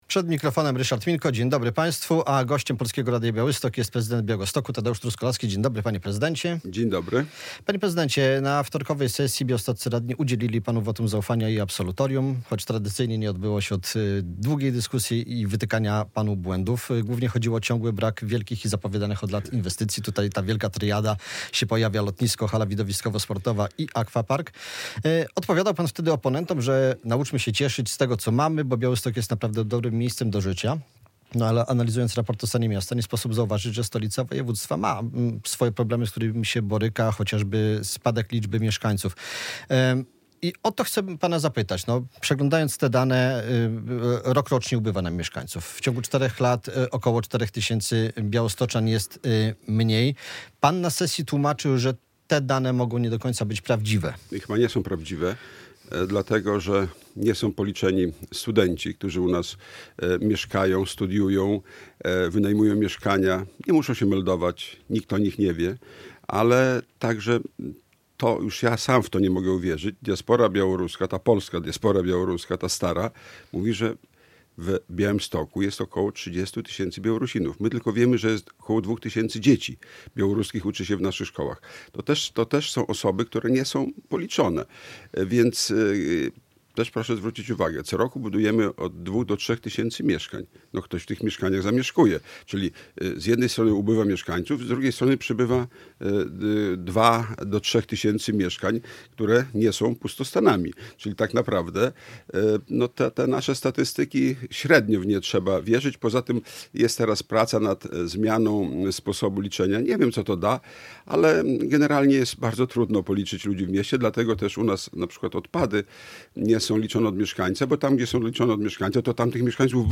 Tadeusz Truskolaski - prezydent Białegostoku
Radio Białystok | Gość | Tadeusz Truskolaski - prezydent Białegostoku